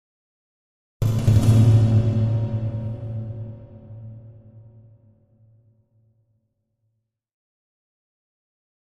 Metal Drum - Several Hits Version 1